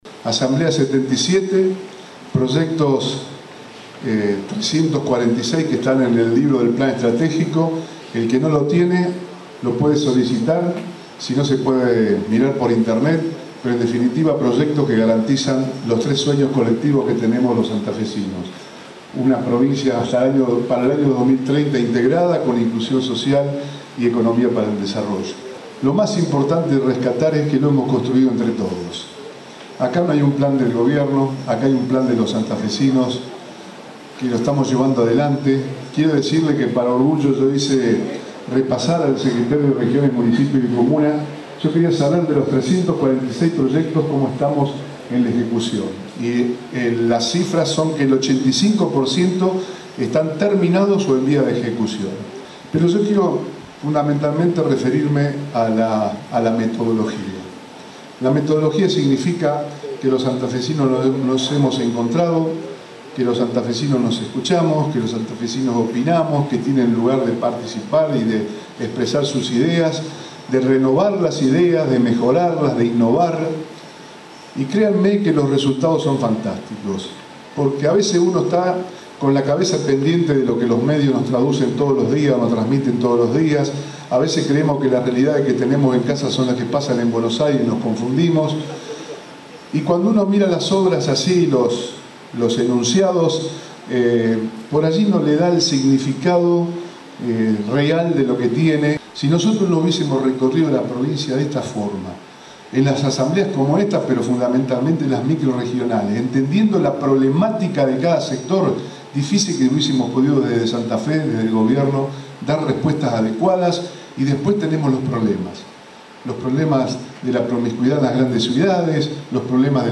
El gobernador encabezó en Venado Tuerto una nueva Asamblea Ciudadana de la Región 5.